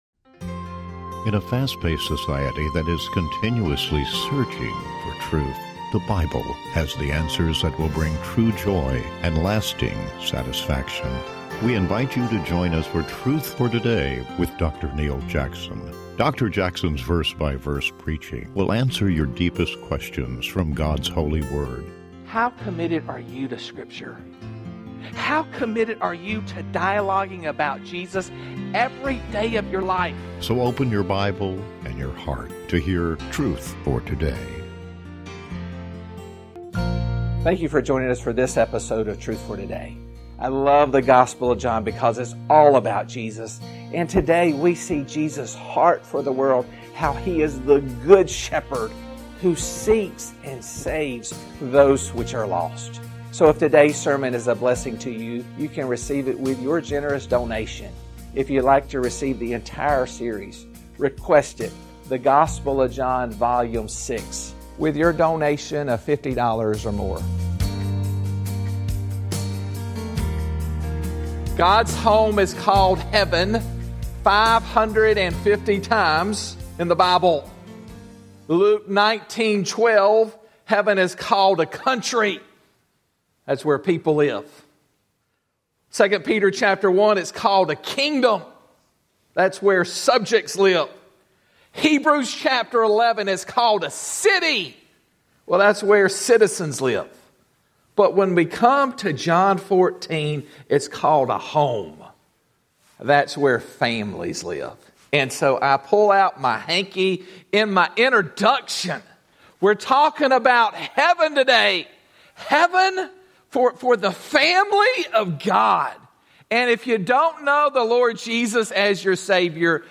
Truth for Today is committed to providing a broadcast that each week expounds God’s Word in a verse-by-verse chapter-by-chapter format.